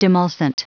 Prononciation du mot demulcent en anglais (fichier audio)
Prononciation du mot : demulcent